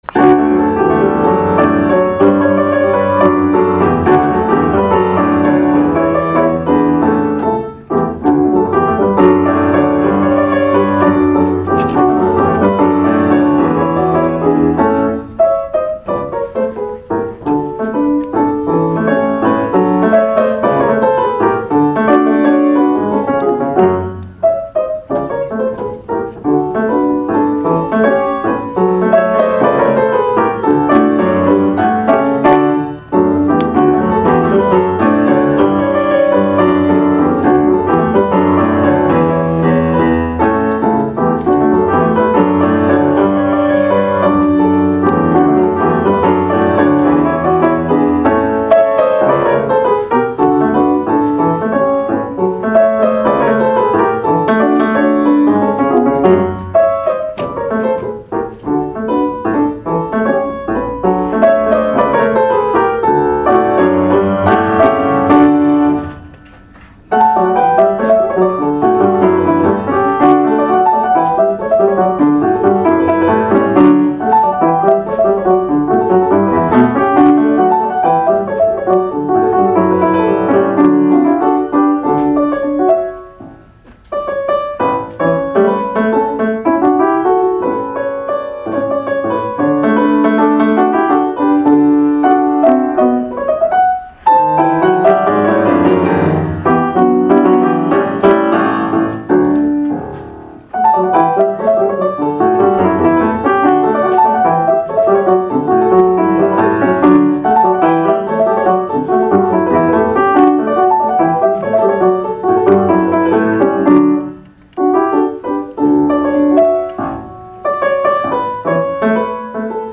recital de música mexicana para piano
El pianista de origen uruguayo
México entre dos siglos de música para piano en la Fonoteca Nacional